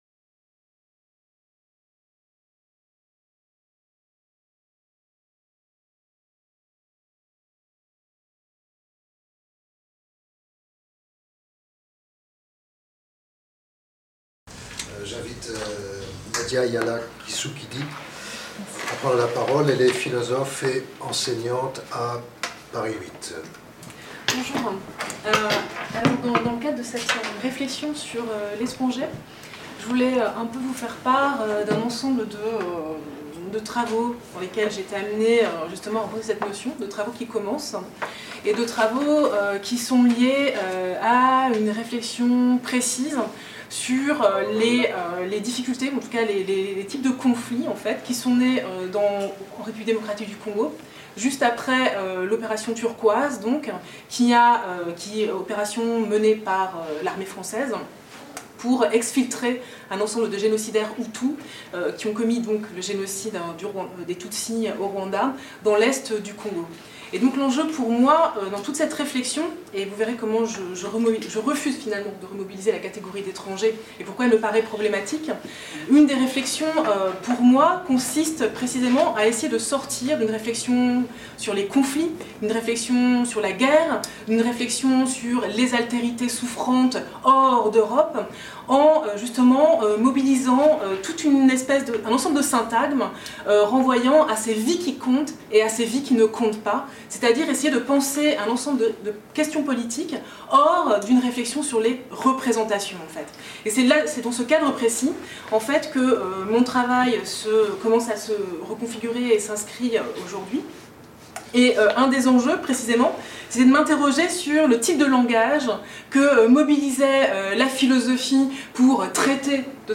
Journée d’étude de la Chaire « Exil et migrations »